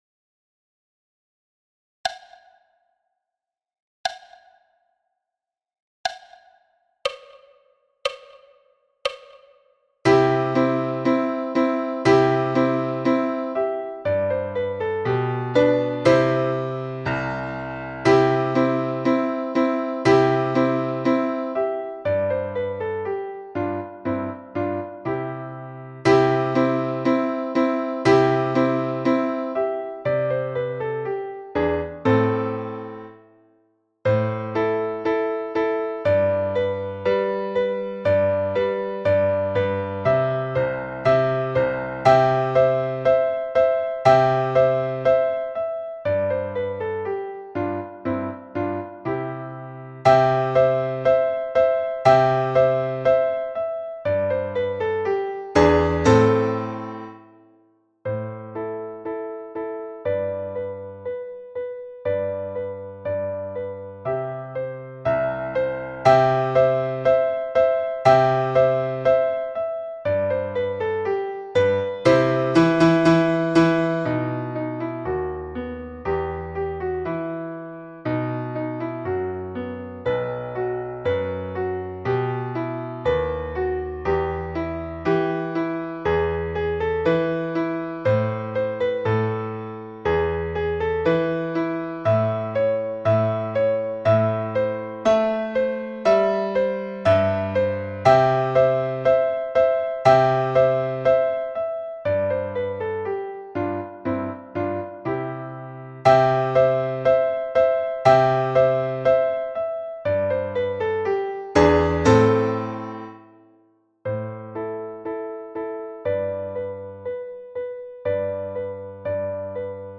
Turkish march – piano à 60 bpm